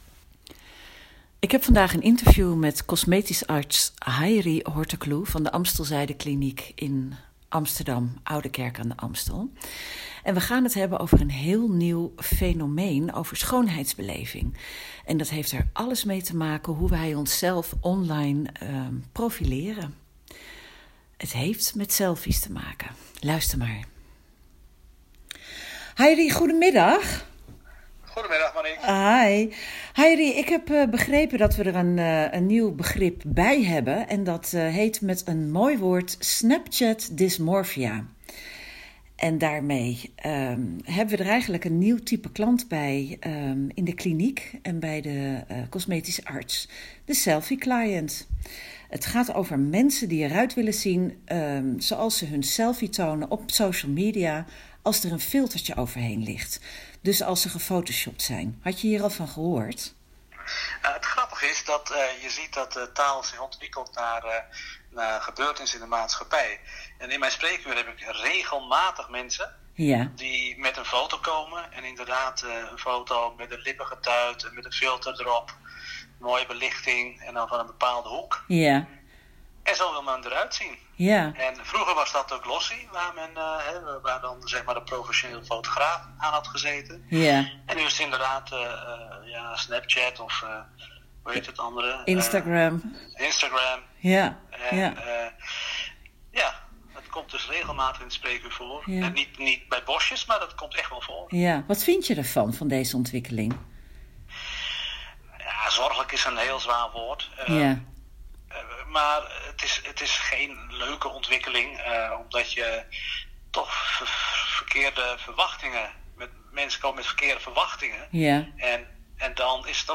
gesprek